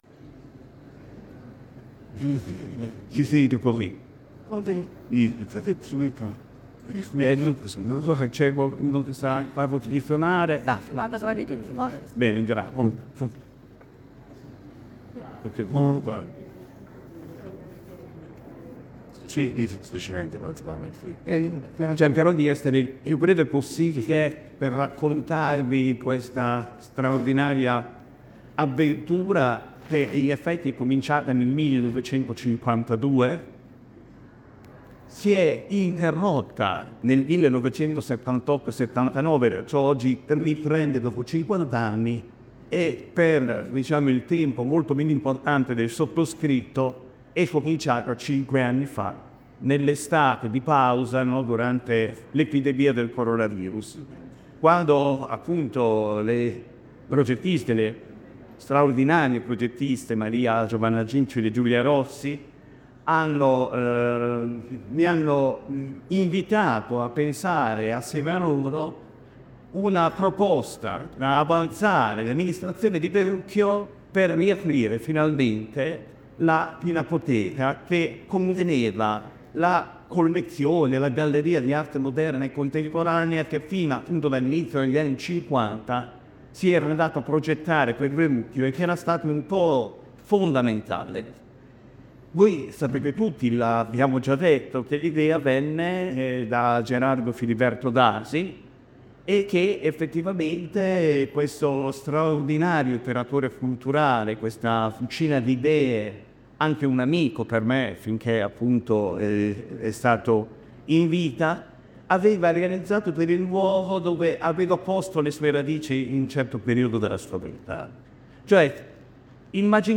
Nel pomeriggio di domenica 6 maggio in tantissimi sono accorsi per la presentazione e l’apertura ufficiale della Pinacoteca.